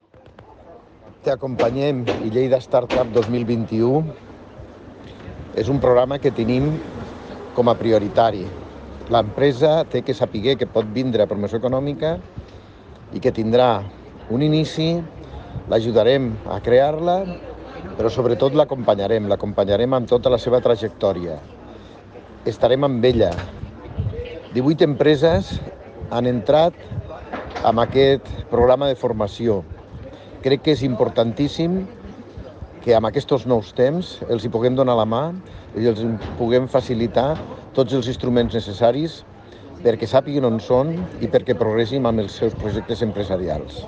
tall-de-veu-del-tinent-dalcalde-paco-cerda